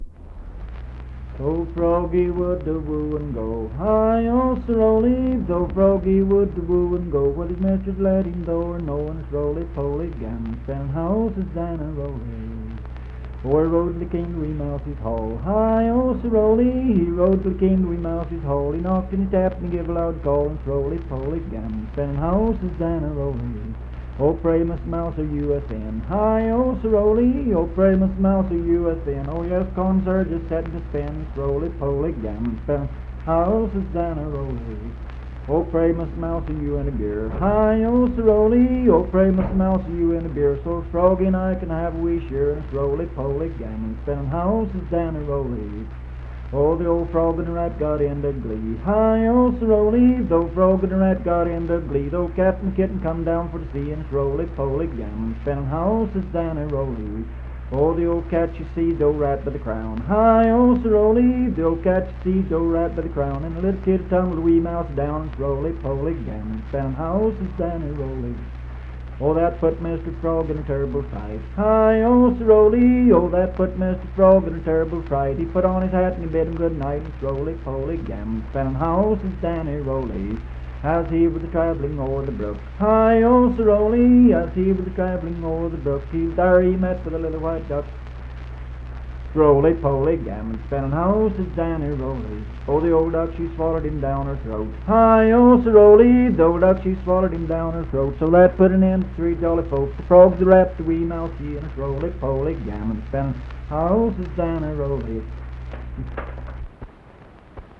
Unaccompanied vocal music
Verse-refrain 9(6).
Dance, Game, and Party Songs, Children's Songs
Voice (sung)